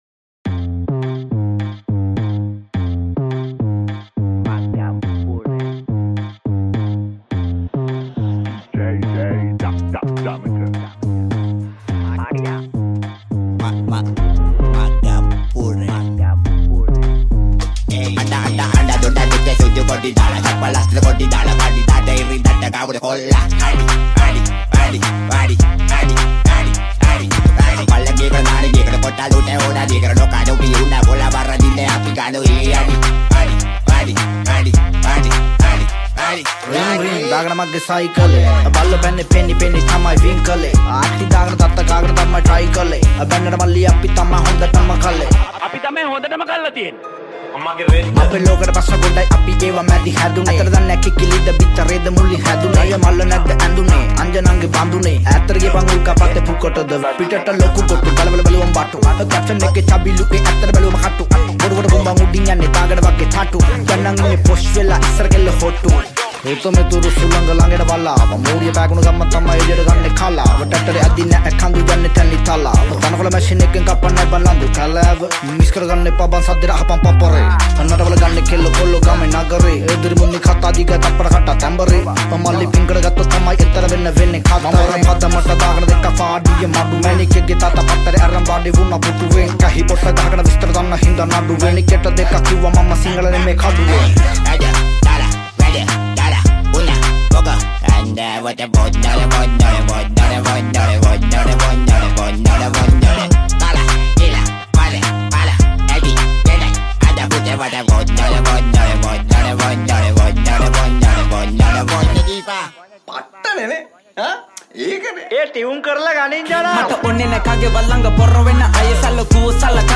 High quality Sri Lankan remix MP3 (3.6).
Rap